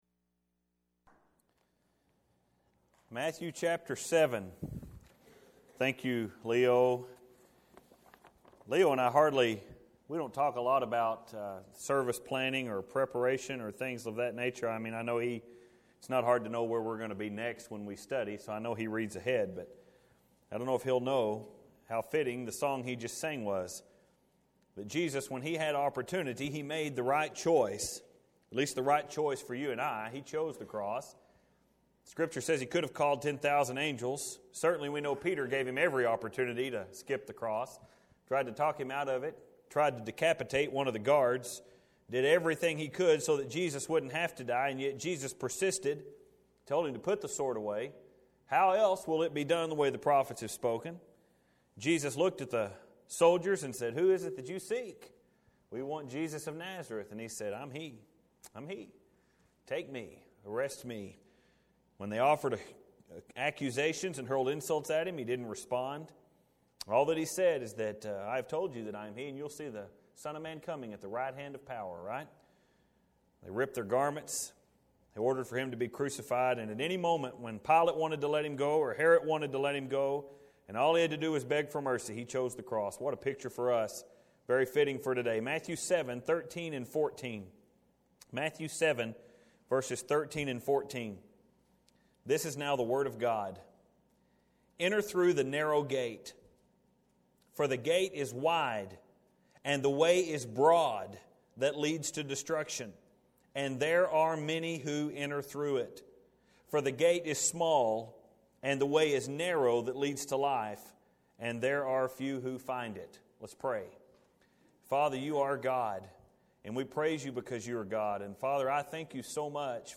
Choosing Righteousness Matthew 7:13-14 We’ve been studying through this Sermon on the Mount, Jesus has dealt with the heart. It has been a sermon chalked full of conviction and even condemnation.